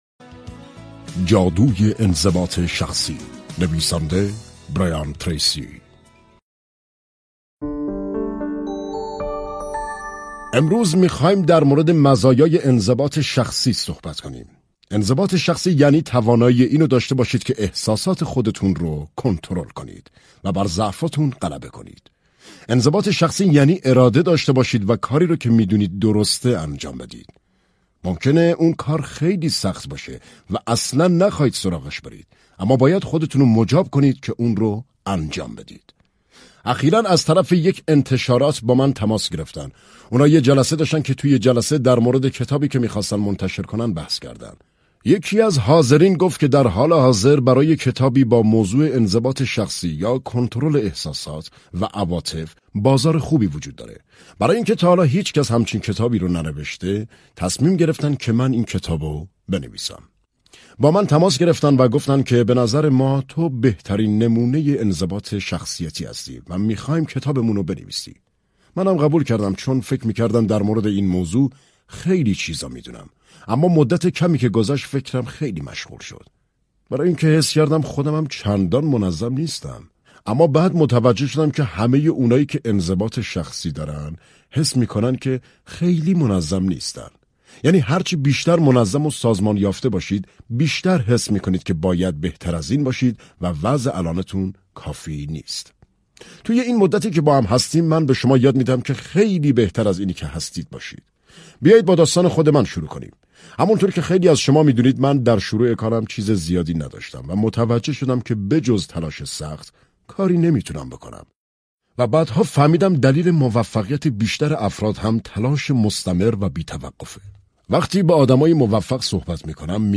دانلود کتاب صوتی معجزه انظباط شخصی